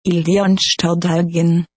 Click on links to play audio files recorded from Google Translate
* Ildjarnstadhaugen: 'j' sounds like 'y', plus the 'd's are at least sometimes silent in the Hedalen dialect